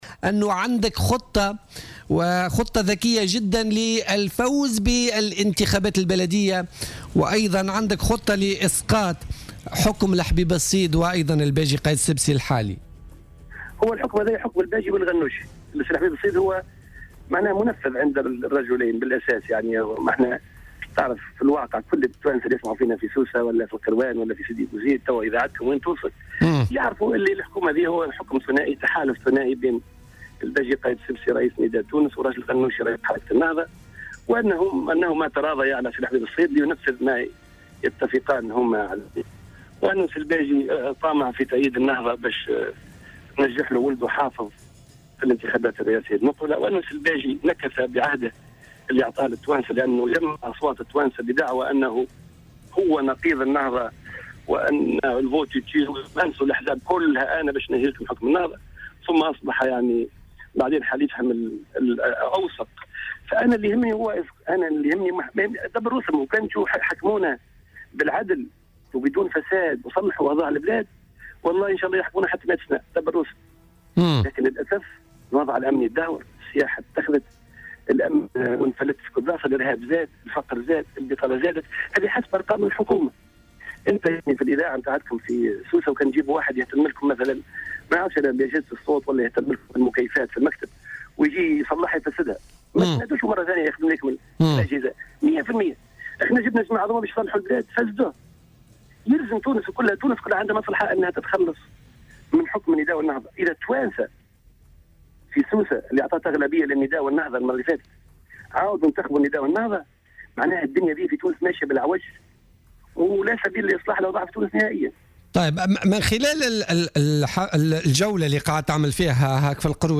أكد رئيس تيار المحبة الهاشمي الحامدي في اتصال هاتفي من قفصة مع برنامج "بوليتيكا" بـ "الجوهرة اف أم" اليوم الاثنين أن هناك تضاربا للمصالح في حكومة الحبيب الصيد وبدعم من الباجي قايد السبسي وراشد الغنوشي.